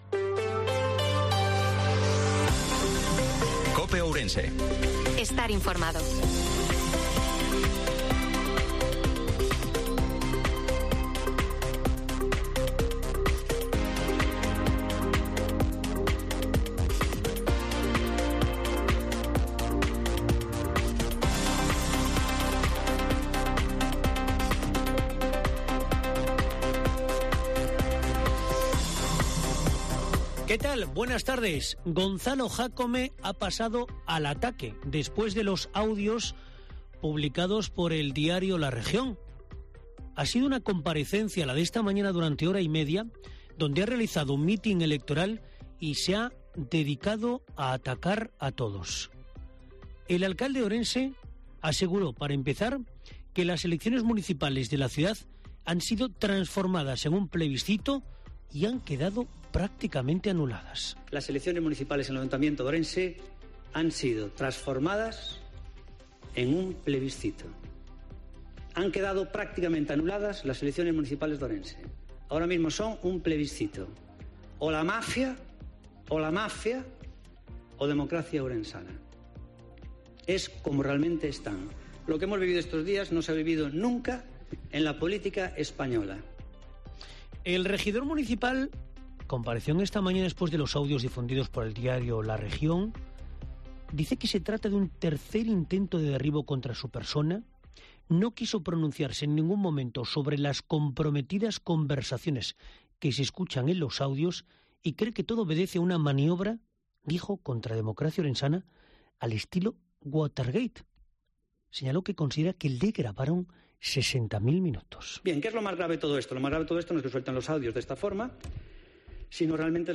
INFORMATIVO MEDIODIA COPE OURENSE-10/05/2023